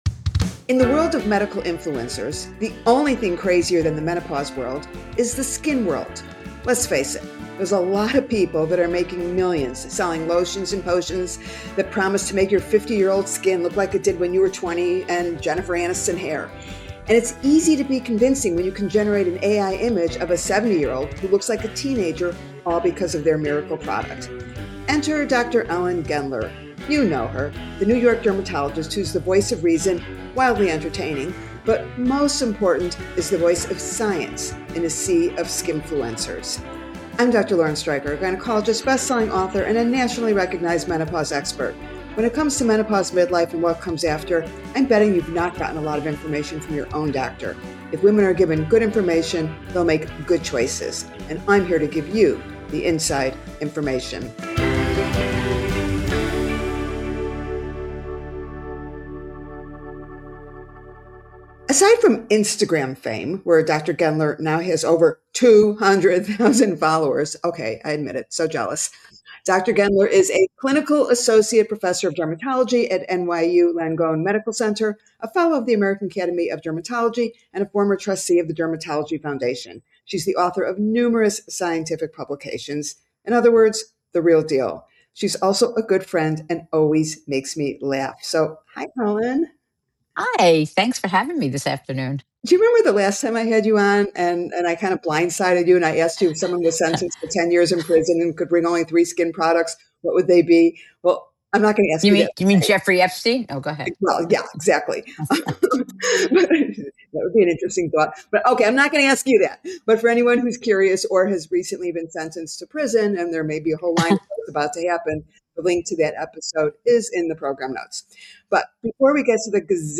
SO strap in for this rapid fire Q and A!